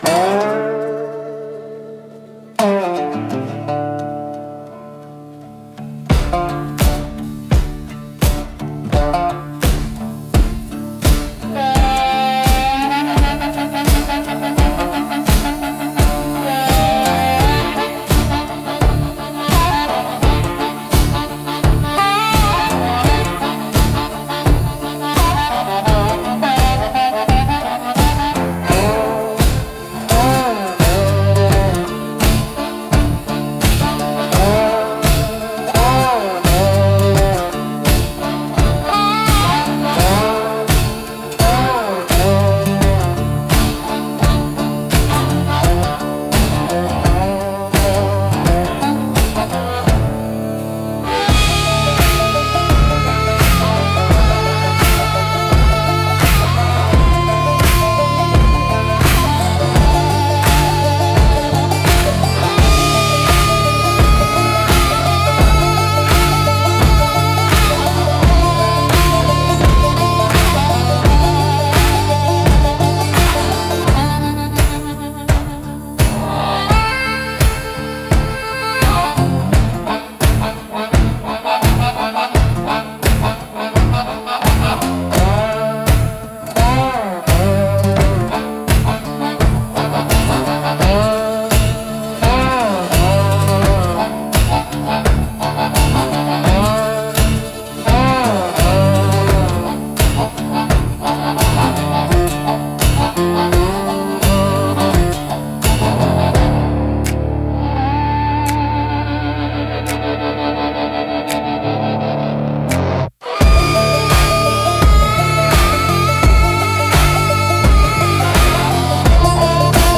Instrumental - Hell or Highwater Bill 2.40